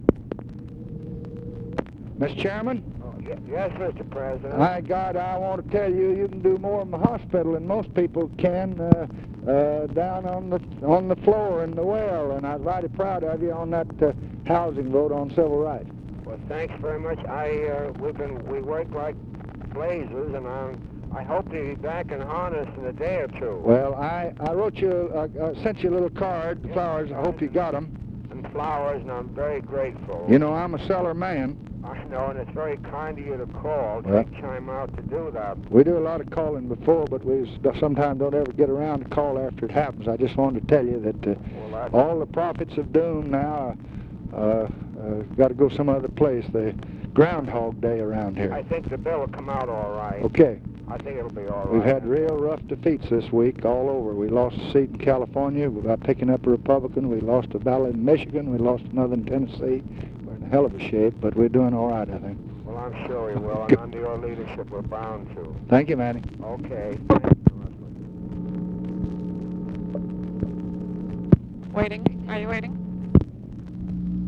LBJ CALLS CELLER WHO IS IN THE HOSPITAL TO THANK HIM FOR HIS WORK ON OPEN HOUSING PROVISION OF CIVIL RIGHTS BILL; LBJ EXPRESSES CONCERN ABOUT DEMOCRATIC LOSSES IN RECENT ELECTIONS
Conversation with EMANUEL CELLER, August 5, 1966